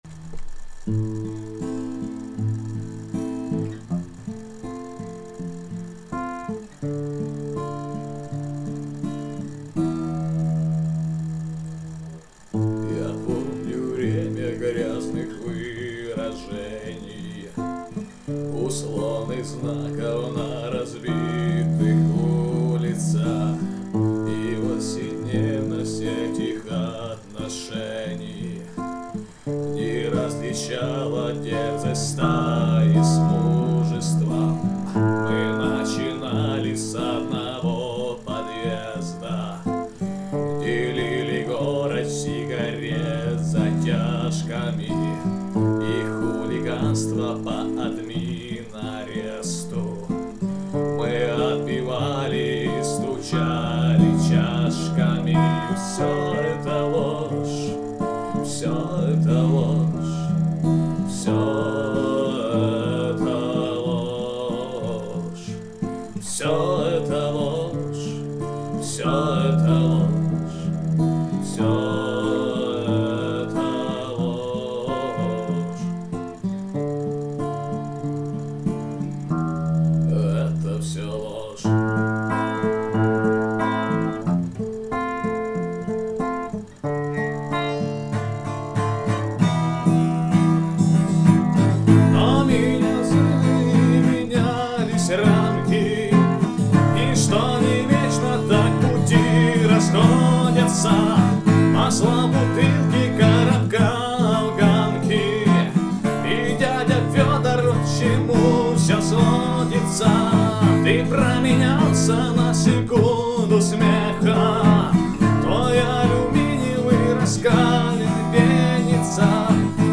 Тут гитарист уже выпил и не может читать слов)